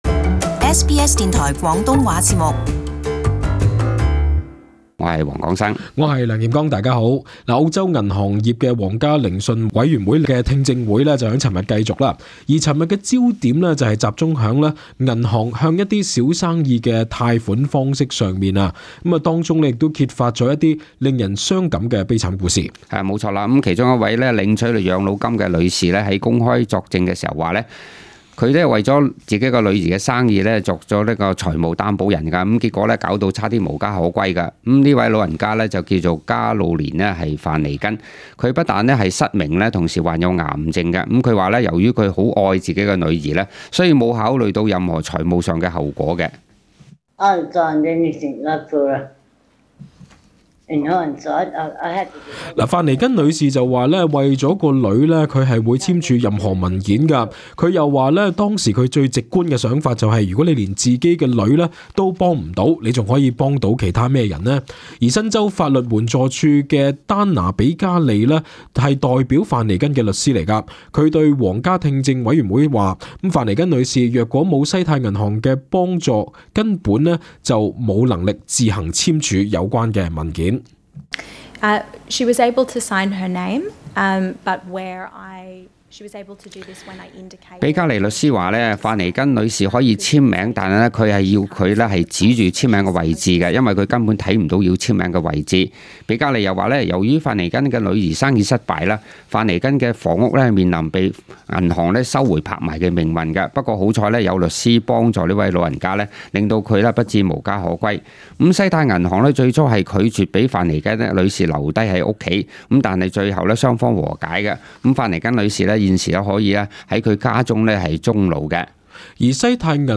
【時事報導】銀行業皇家委員會聽證會 揭露悲慘故事